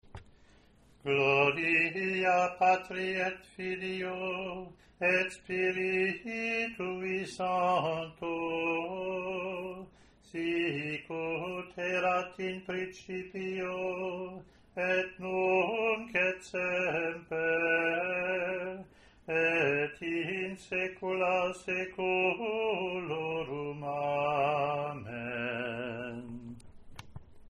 Hear below the how the “Gloria Patri” sounds as we put Intonation, Recitation, Mediation and Termination together:
Recitation at approximately 220 hz (A3):